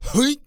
XS蓄力04.wav
XS蓄力04.wav 0:00.00 0:00.48 XS蓄力04.wav WAV · 41 KB · 單聲道 (1ch) 下载文件 本站所有音效均采用 CC0 授权 ，可免费用于商业与个人项目，无需署名。
人声采集素材